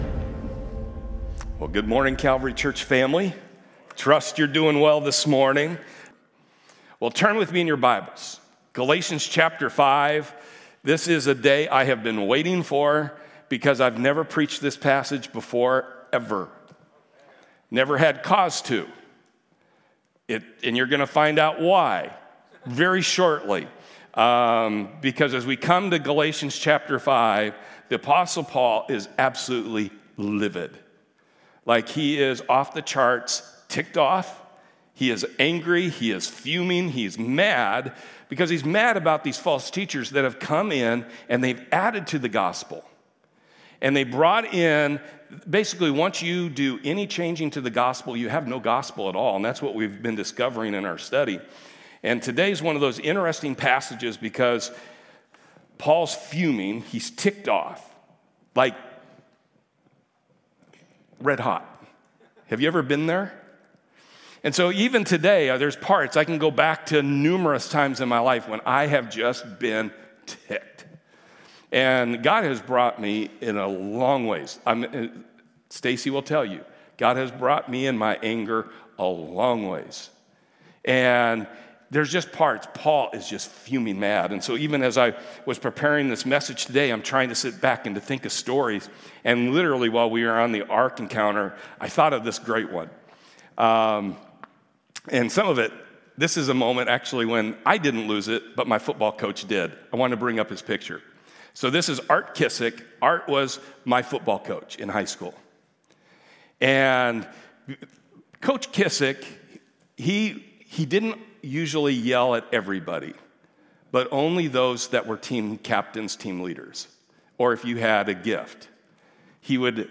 Series: The Book of Galatians Service Type: Sunday